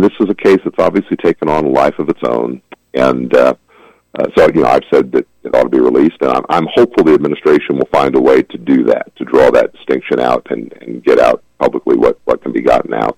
That’s the take from Kansas Second District Congressman Derek Schmidt on KVOE’s airwaves late last week as he commented on the ongoing debate on how the trump administration should handle the Jeffrey Epstein files.